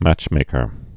(măchmākər)